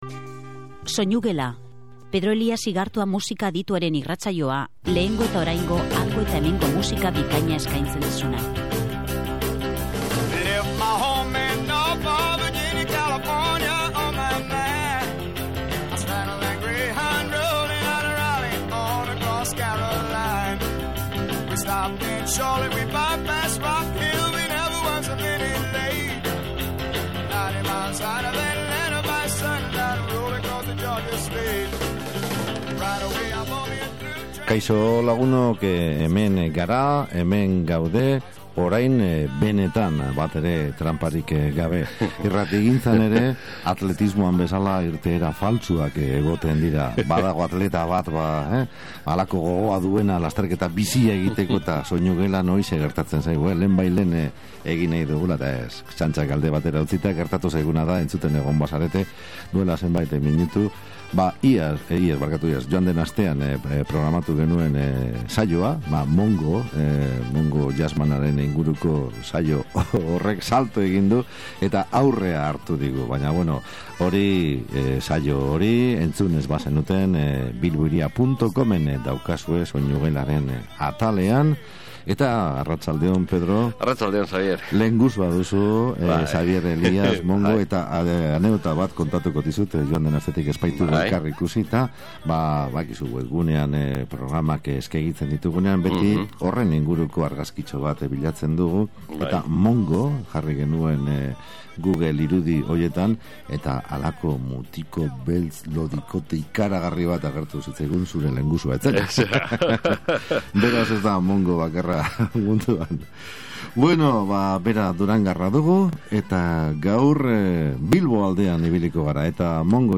Soinugelako estudioetara etorri da eta ordubeteko elkarrizketa ezin atseginago batean hurbileko historia hori kontatu digu.